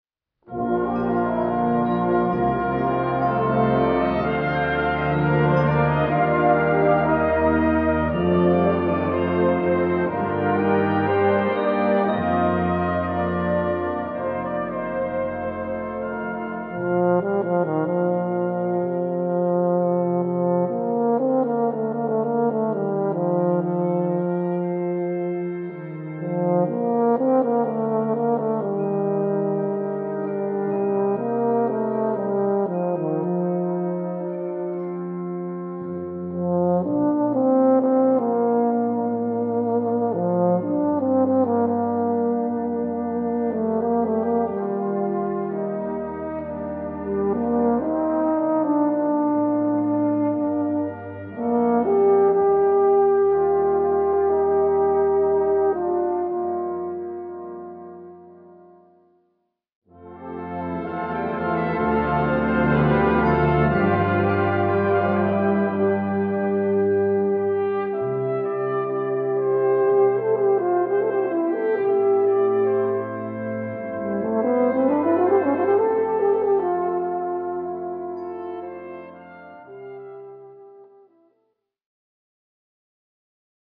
uphonium Solo